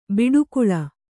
♪ biḍu kuḷa